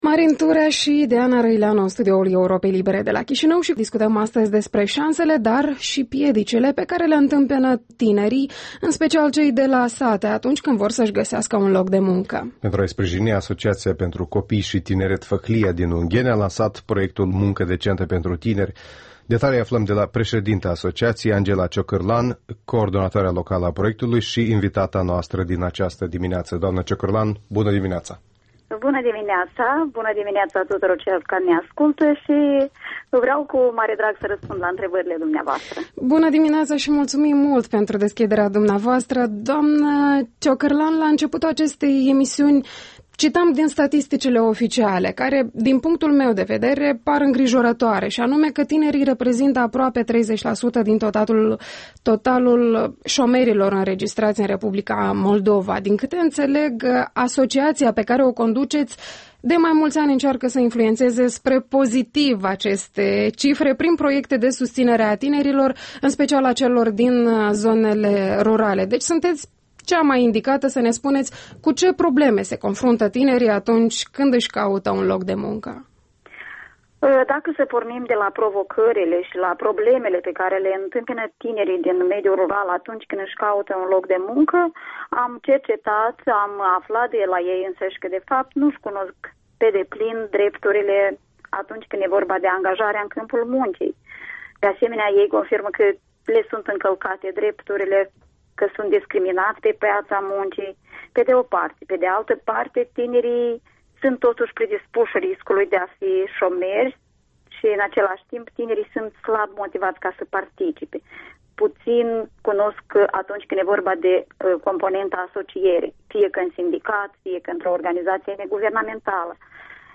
Interviul dimineții